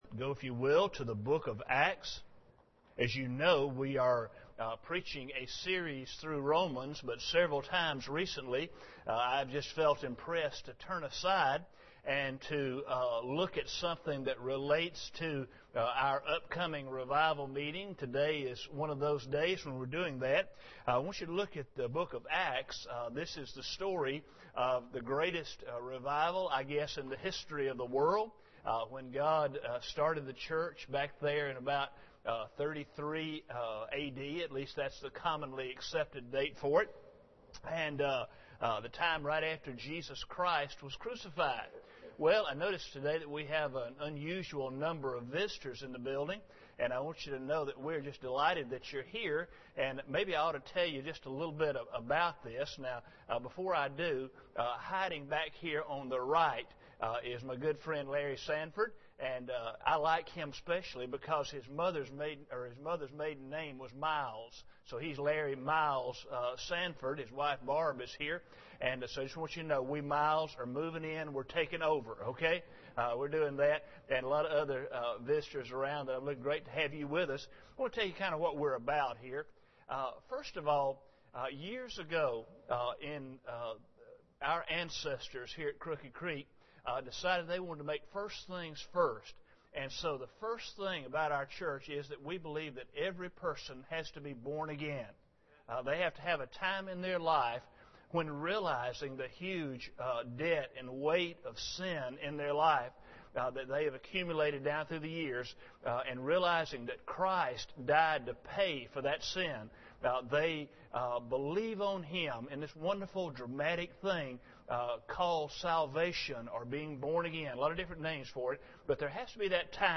Acts 1:8 Service Type: Sunday Morning Bible Text